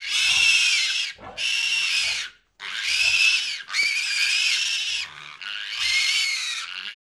Index of /90_sSampleCDs/E-MU Producer Series Vol. 3 – Hollywood Sound Effects/Water/Pigs
BABY PIG 00R.wav